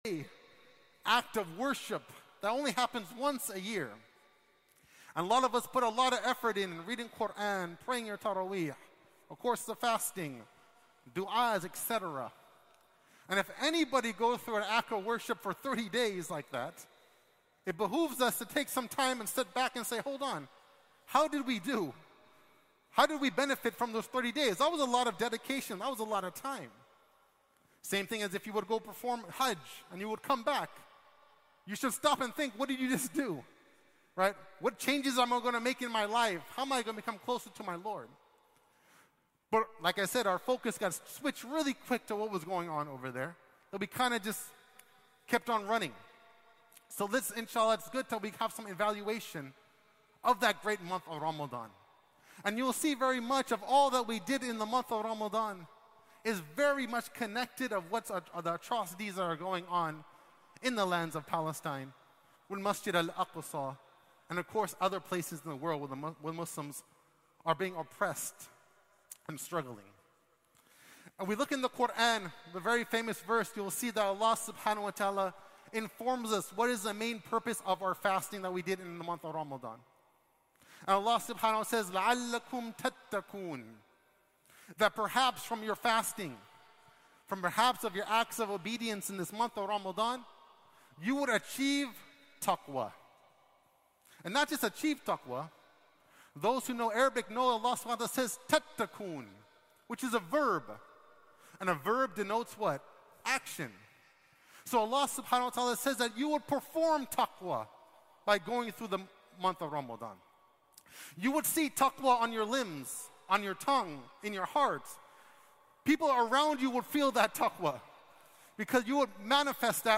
Friday Khutbah - "Post-Ramadan Evaluation"